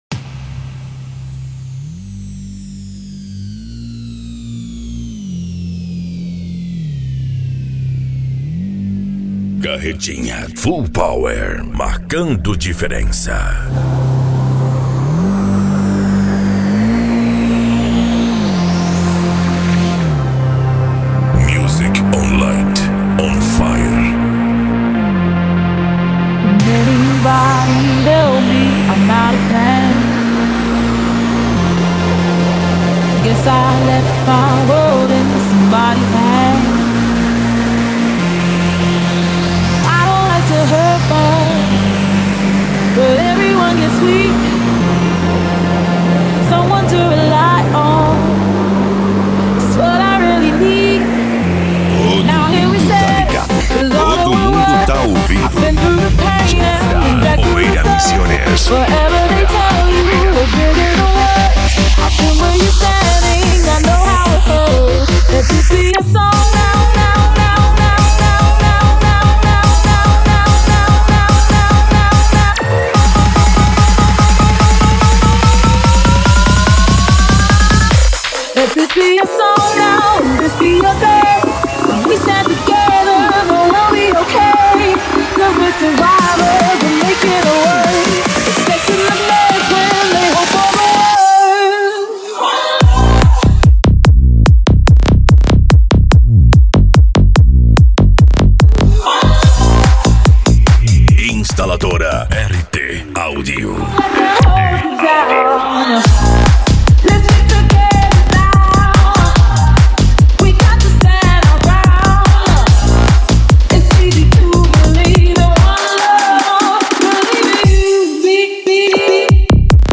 Bass
Remix
Musica Electronica